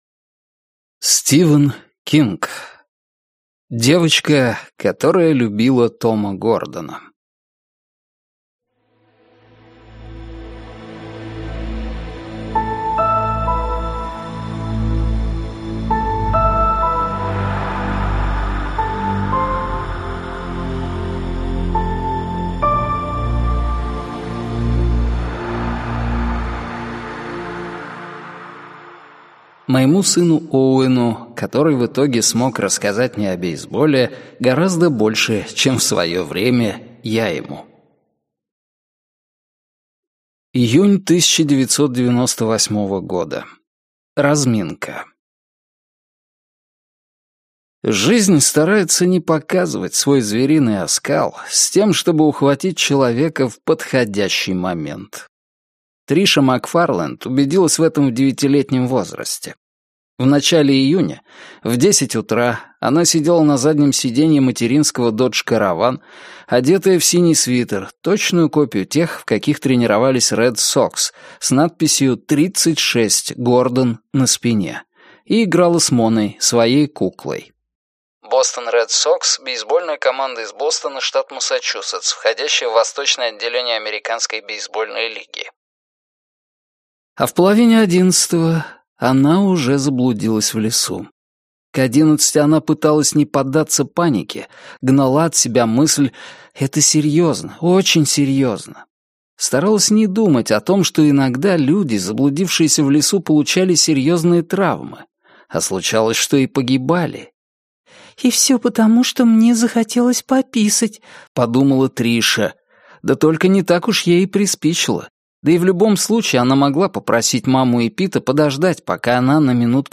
Аудиокнига Девочка, которая любила Тома Гордона - купить, скачать и слушать онлайн | КнигоПоиск